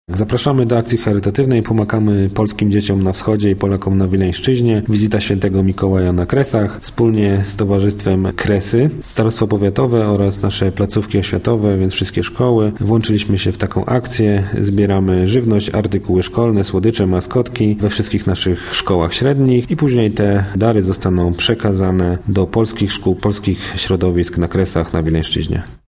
– mówił Krzysztof Dziuba, wicestarosta wieluński.